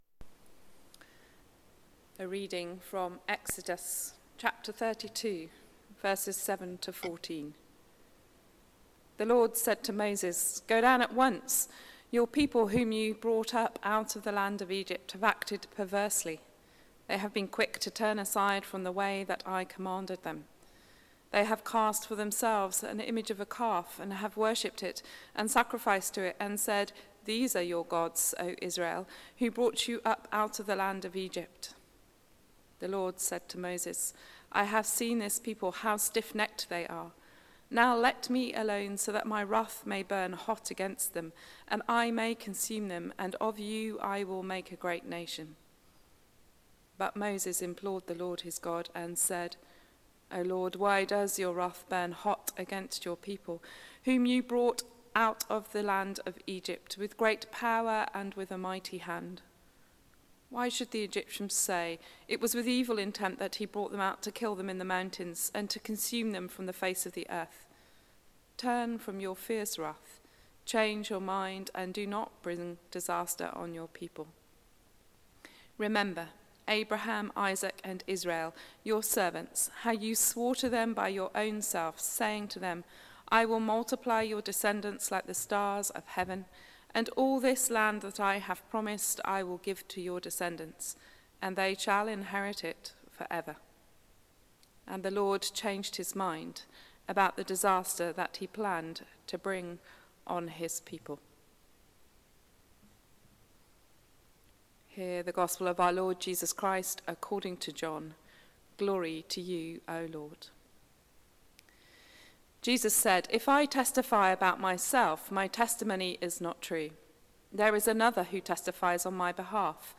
Sermon: Life Before You | St Paul + St Stephen Gloucester